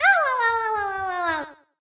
toadette_burning.ogg